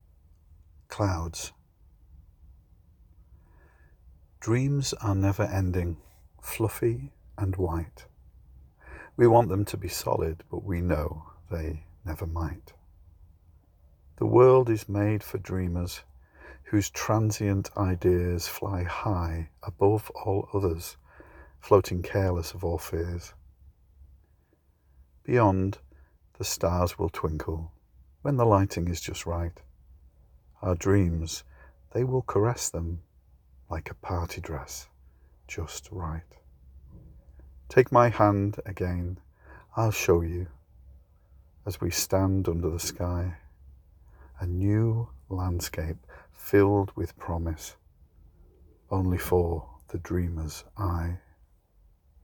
Your voice and the way in which you pause at proper moments made it quite enjoyable.